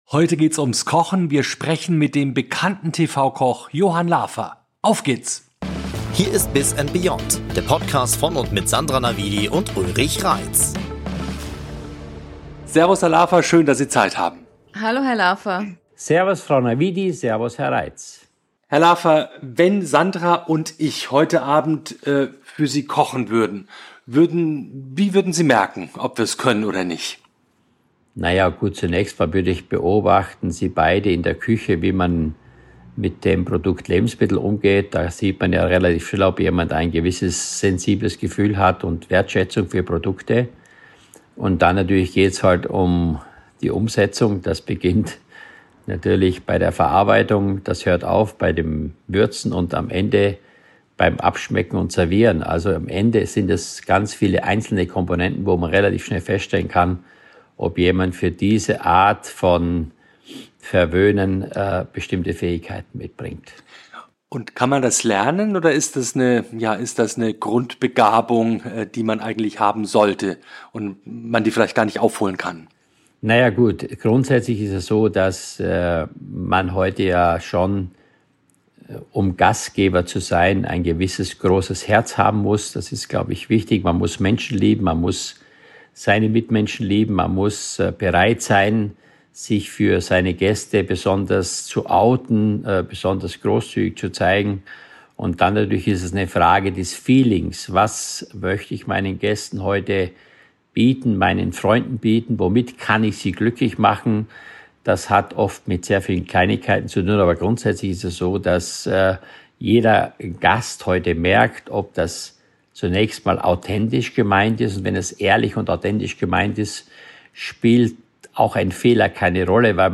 Beschreibung vor 2 Wochen Vom Zwiebelschälen zum TV-Star – und fast wieder zurück: Johann Lafer spricht über seinen Weg, harte Rückschläge und die wichtigsten Lektionen seiner Karriere. Warum Talent allein nicht reicht, Gäste sofort spüren, ob etwas ehrlich ist – und wie er heute das Essen in der First und Business Class der Lufthansa neu denkt. Ein Gespräch über Erfolg, Fehler und die Frage: Was macht gutes Essen wirklich aus?